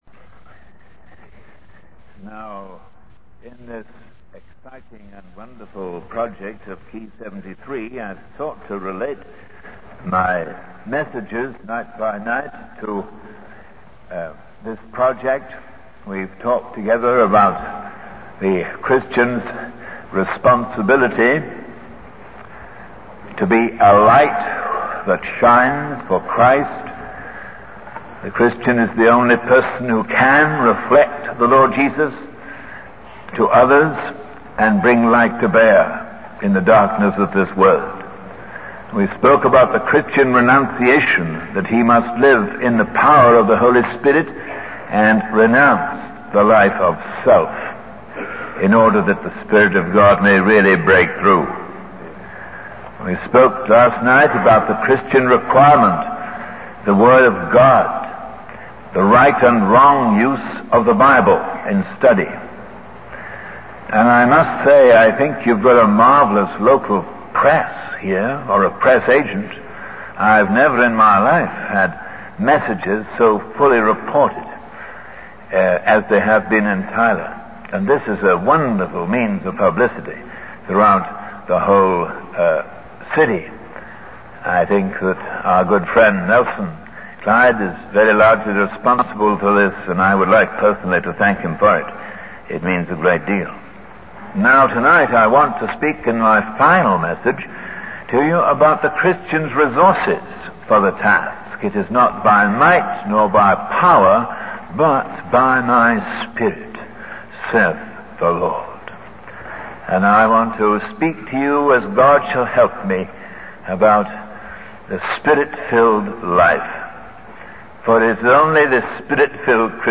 In this sermon, the speaker emphasizes the importance of the Spirit-filled life for Christians. He highlights that it is not by human strength or power, but by the Holy Spirit that believers can confront the world with a message of life and power.